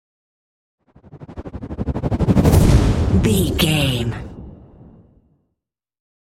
Whoosh to hit trailer long
Sound Effects
Fast paced
In-crescendo
Atonal
dark
futuristic
intense
tension